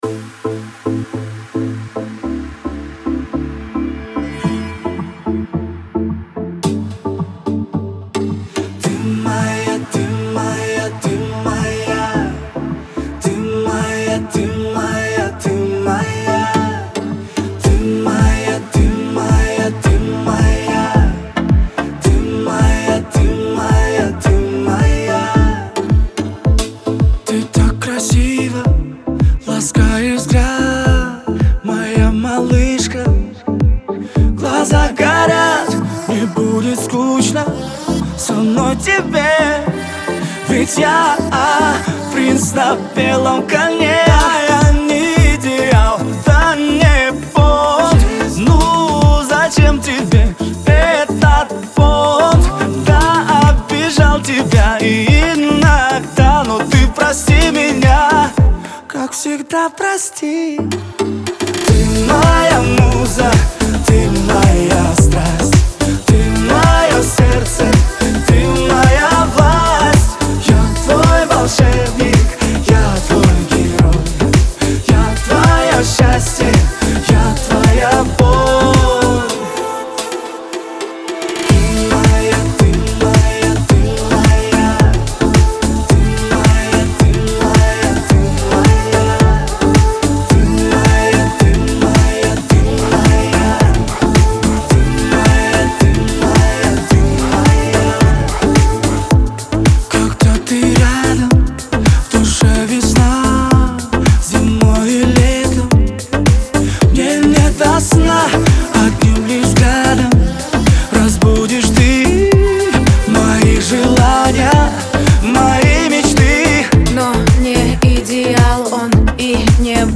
представляет собой романтический поп-балладный трек.